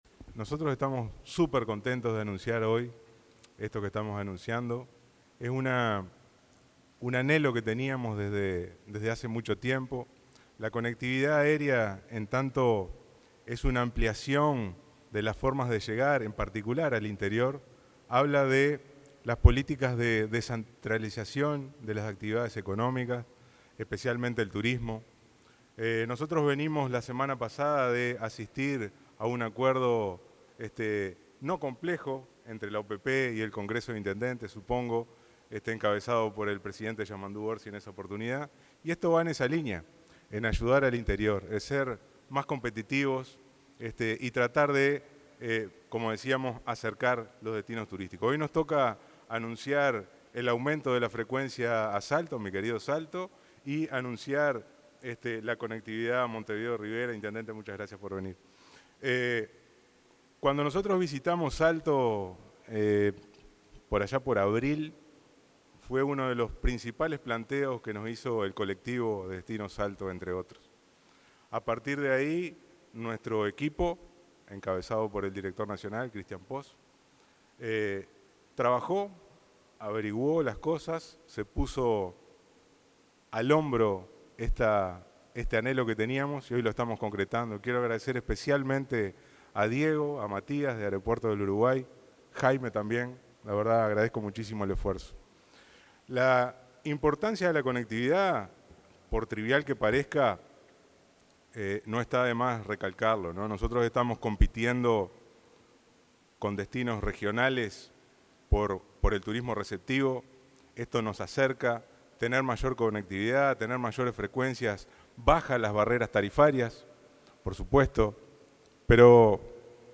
Palabras del ministro de Turismo, Pablo Menoni